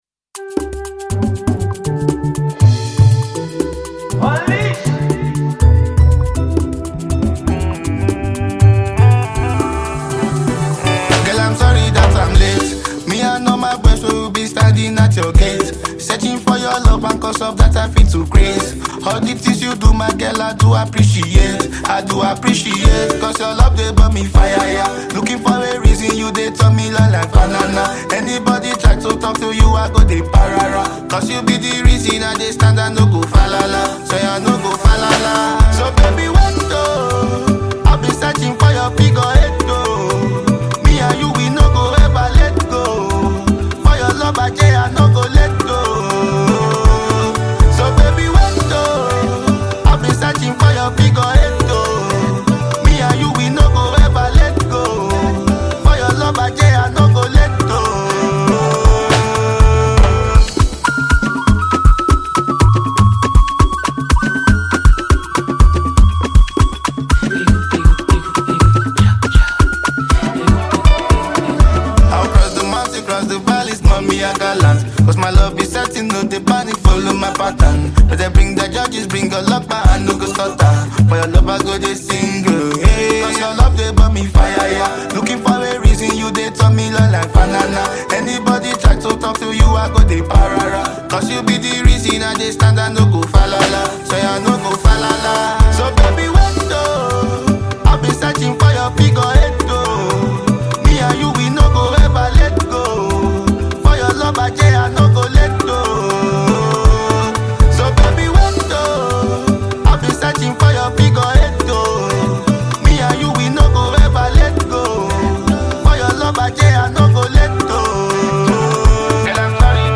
love song
Afro-fusion rhythms and soul-stirring melodies